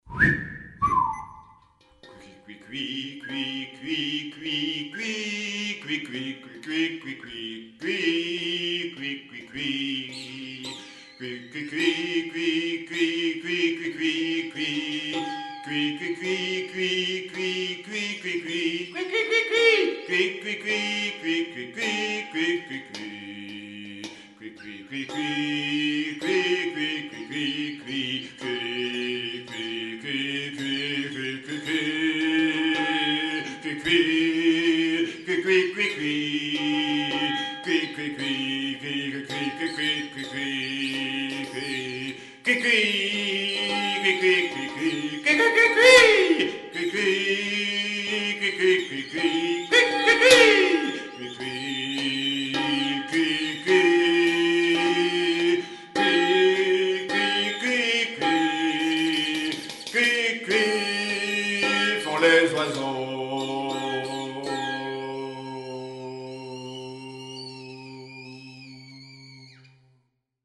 avec, à la bassine, la participation du puni